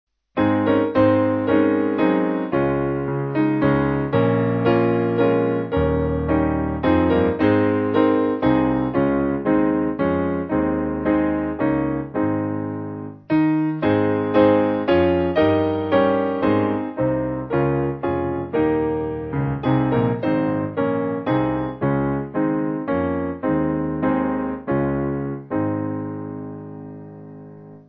Simple Piano
(CM)   4/Ab